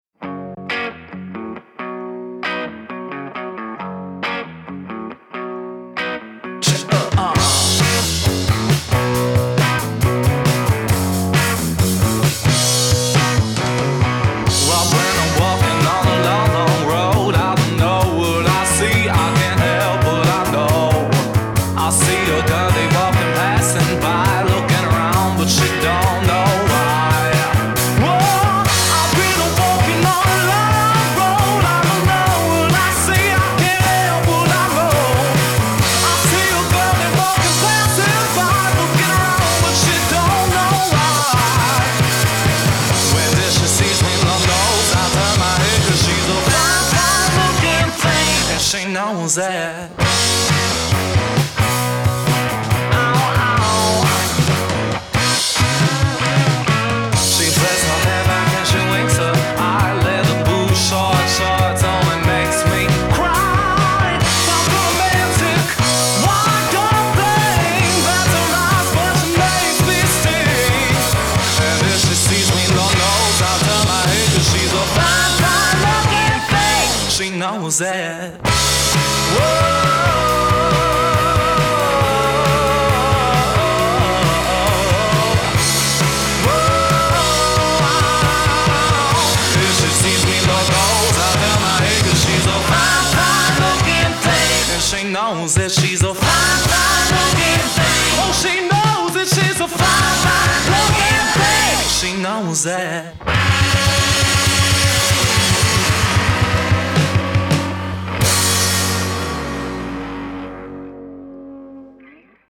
Genre: Rock 'n' Roll, Soul